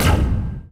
Erekir turret SFX (of varying quality)
malignShoot.ogg